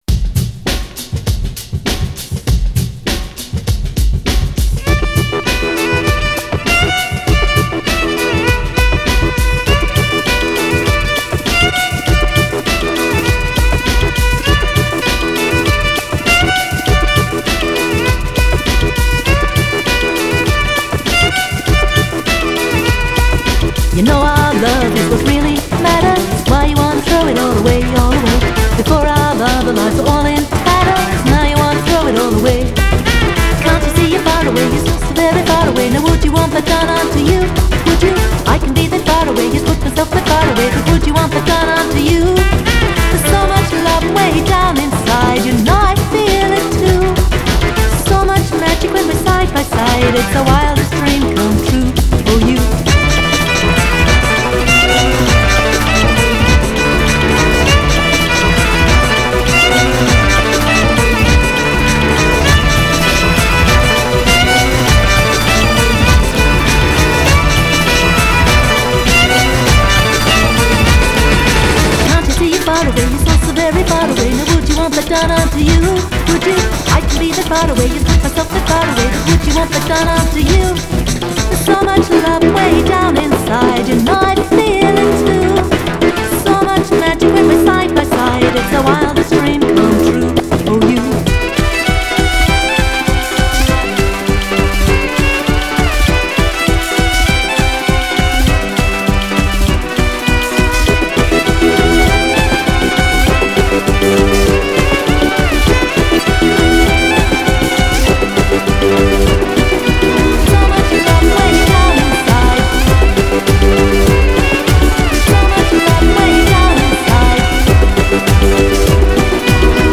solo dance CD